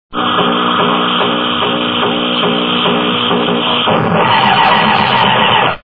Attaque martienne      Destruction     Rayon vert
attaque2.wav